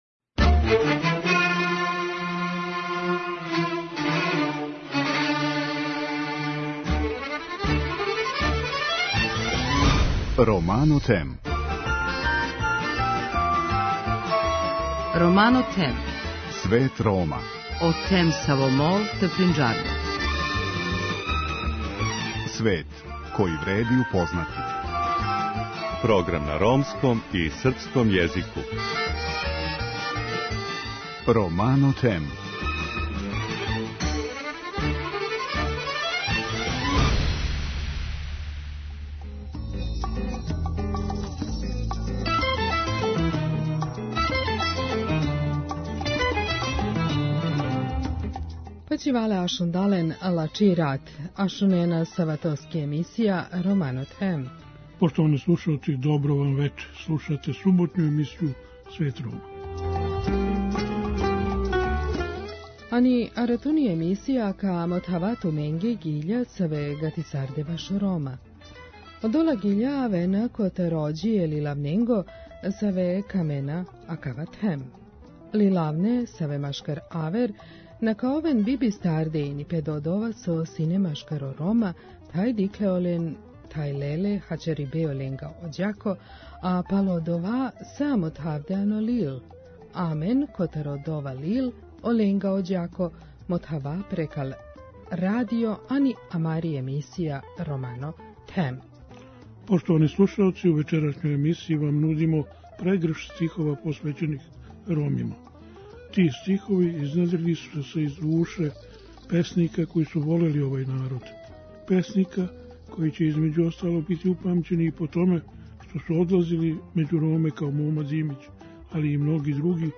Вечерас говоримо стихове неких од песника који представљају културу и традицију Рома, а чија поезија је забележена у Антологији.